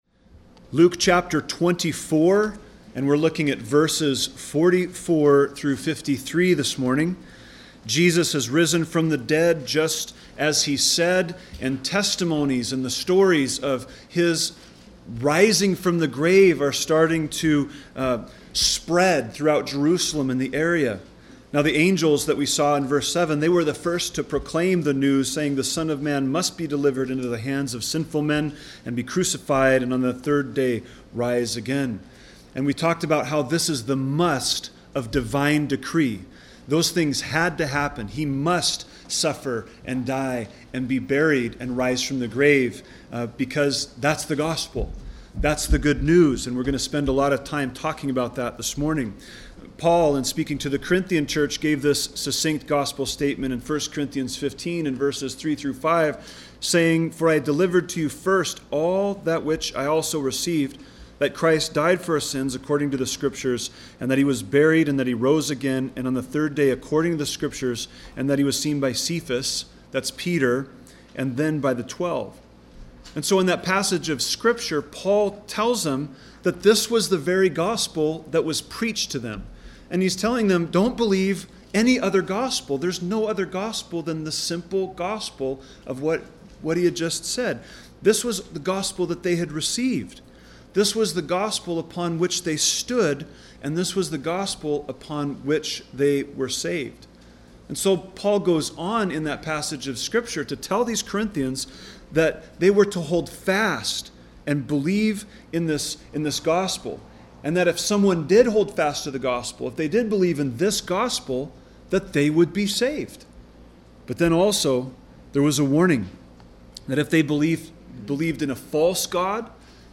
A message from the series "Gospel of Luke."